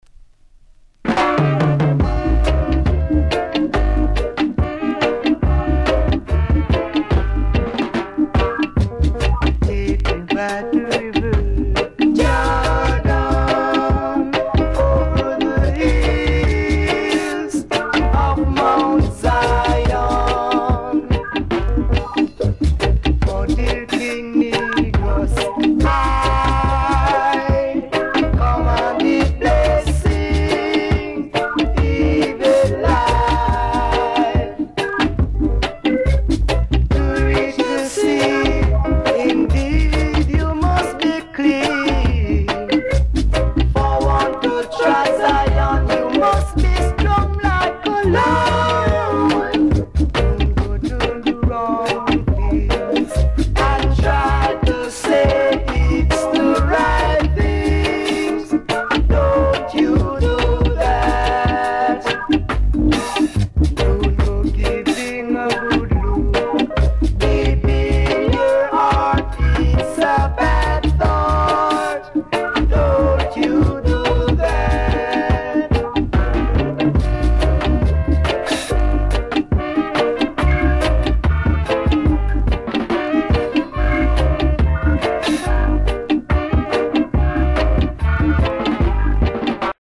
two remaining vocalists
trombonist
percussions